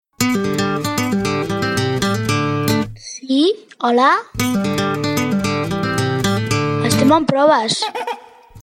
Identificació en els primers dies d'"emissió" per Internet, en proves.